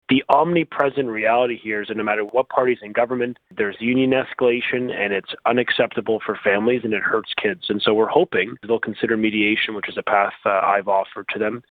Education Minister Stephen Lecce spoke to Quinte News stating they want to see students in schools, and mediation is on the table with OECTA (Catholic), EFTO (public elementary), OSSTF (public secondary) and AEFO (French) unions.